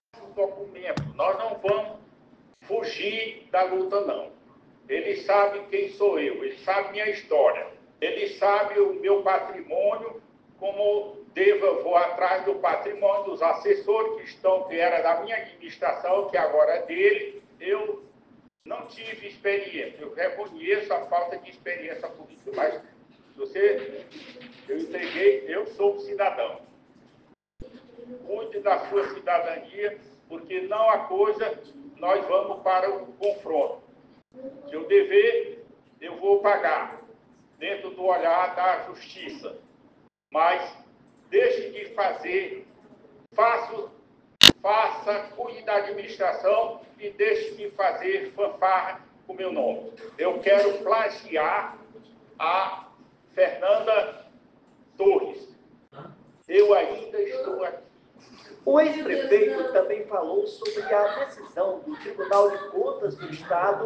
Em entrevista à TV Clube na manhã desta quarta-feira (7), o ex-prefeito Dr. Pessoa (PRD) comentou as declarações do atual prefeito Silvio Mendes (União Brasil) sobre um rombo nos cofres da Prefeitura de Teresina, que chegaria a R$ 3 bilhões.